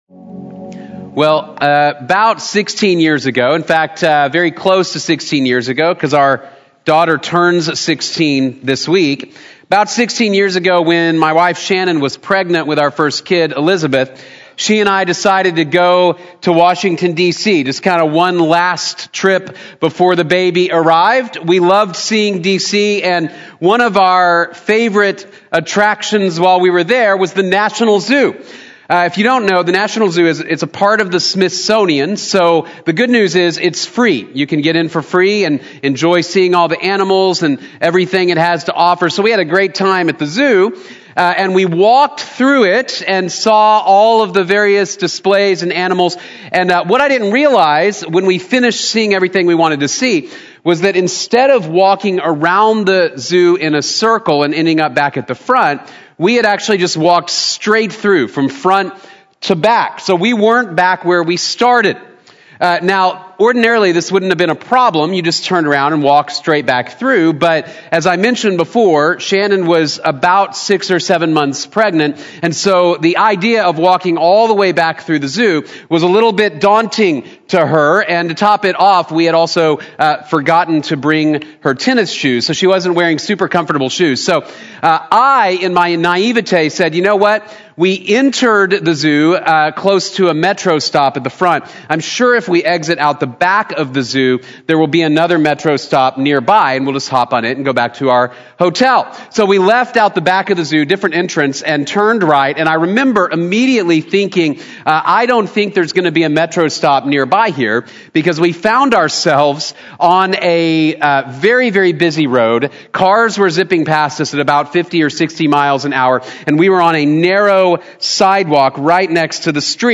Where We’ve Been and Where We’re Going | Sermon | Grace Bible Church